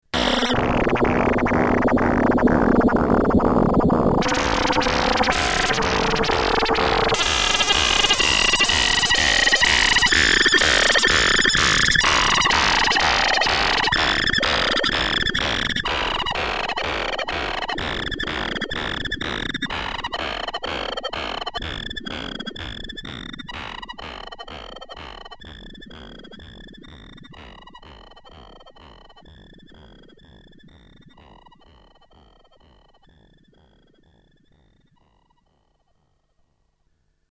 Complete modulation fest. The sequencer is taken in as another modulation source for EG1, in turn affecting the LFO, in turn affecting lots of stuff, and some more modulation that I just can't remember.